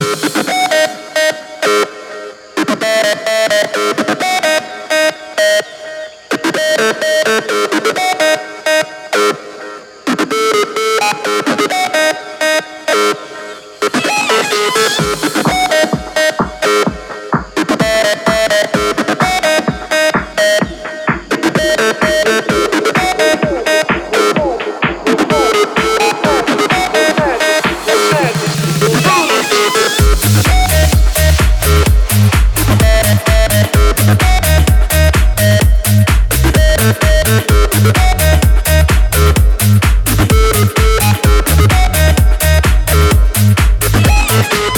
• Качество: 320, Stereo
ритмичные
громкие
remix
Electronic
без слов
качающие
клубняк
Стиль: house